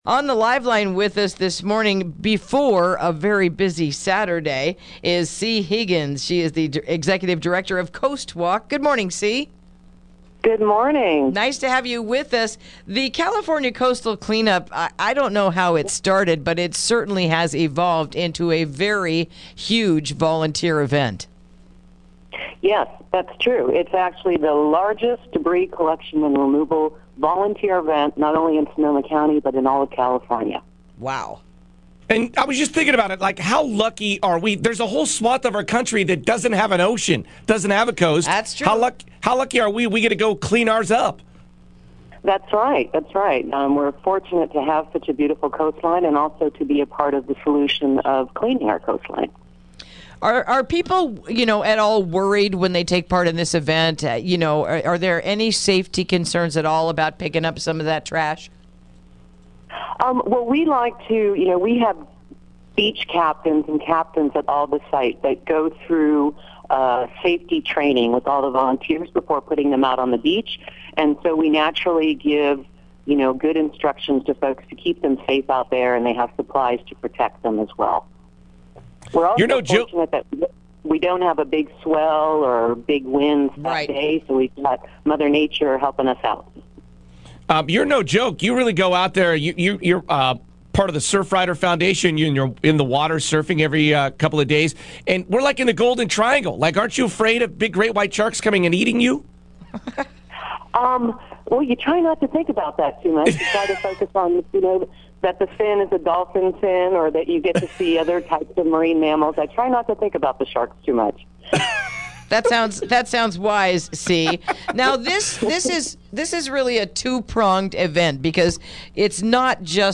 Interview: California Coastal Cleanup this Weekend